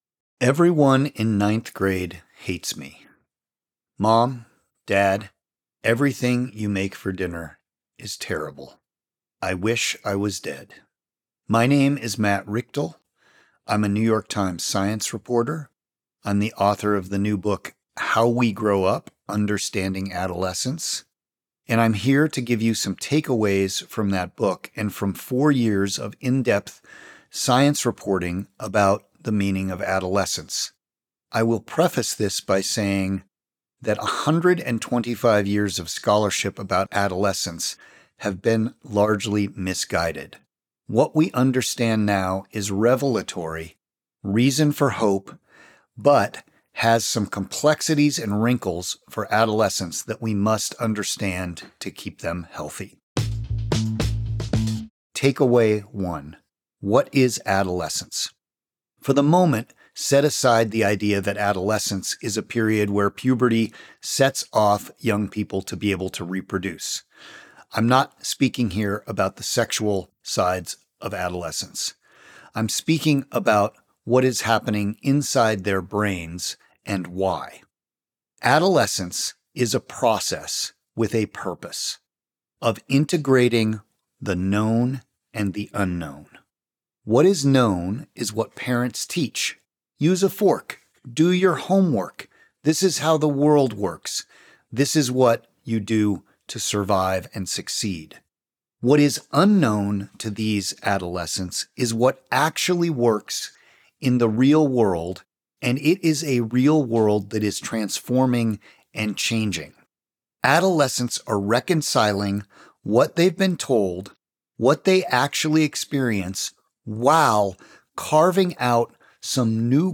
Listen to the audio version—read by Matt himself—below, or in the Next Big Idea App.